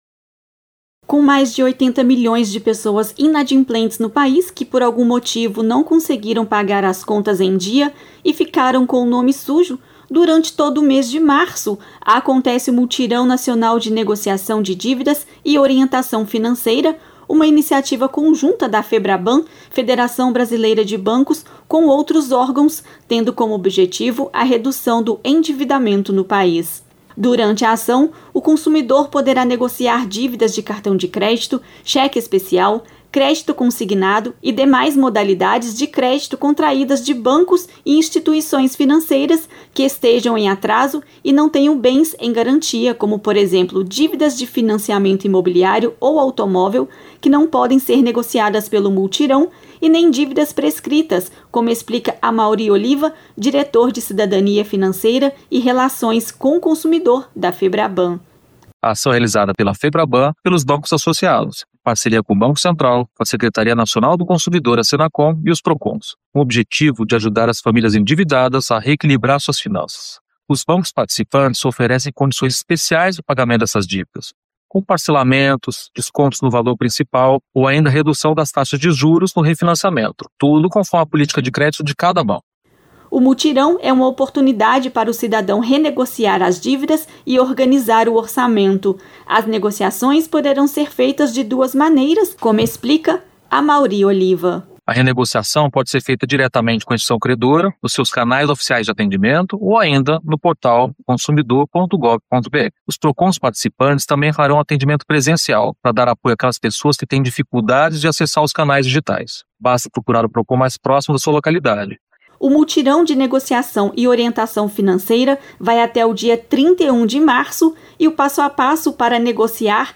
Os detalhes na reportagem